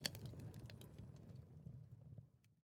fire_crackle5.ogg